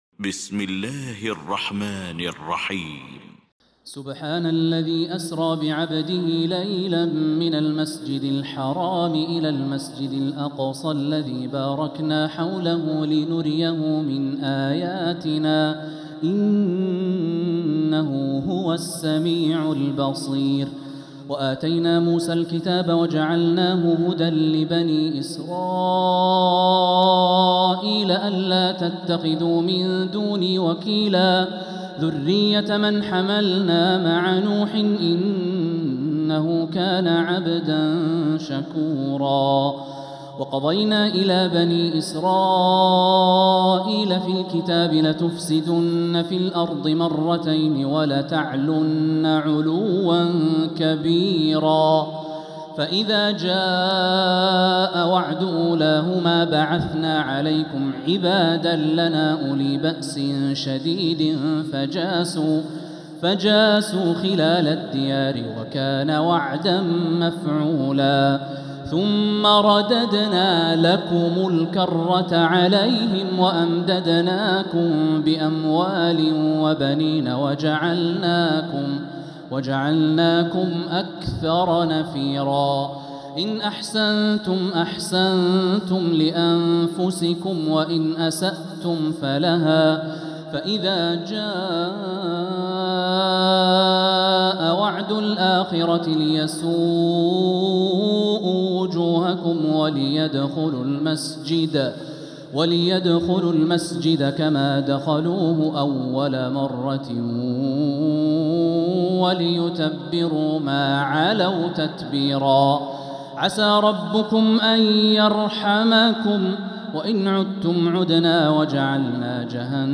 المكان: المسجد الحرام الشيخ